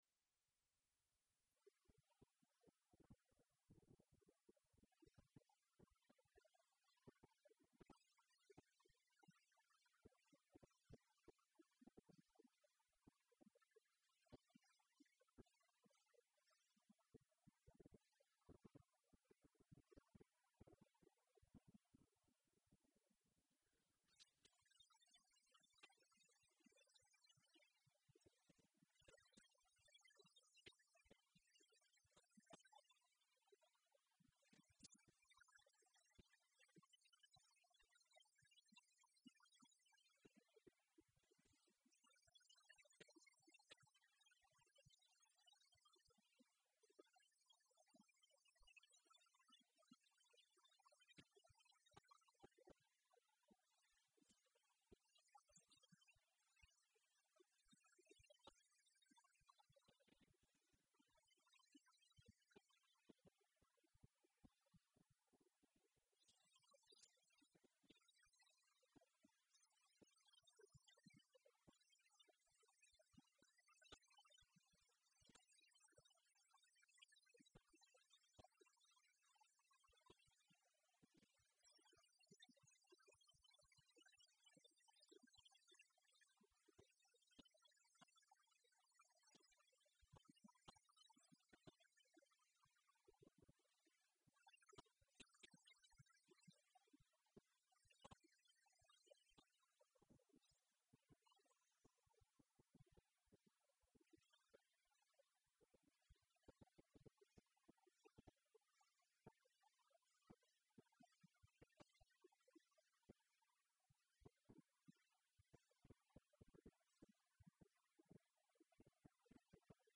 Записи делались с радио и телевизора на магнитофон "Днiпро-12М" на скорости 9,53 см/сек.
Поет Н. Обухова.